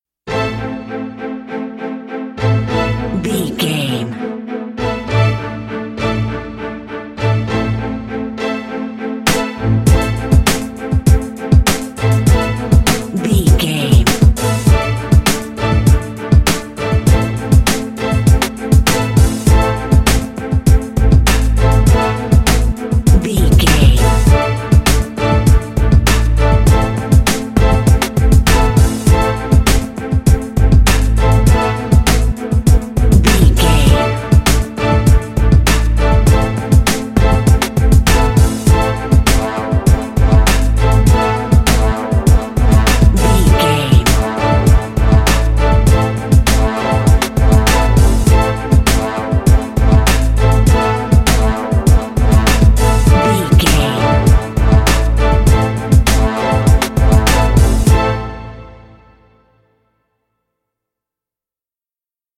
Aeolian/Minor
synthesiser
drum machine
strings
horns
bass guitar
hip hop
soul
Funk
neo soul
acid jazz
confident
energetic
cheerful
bouncy
Triumphant
funky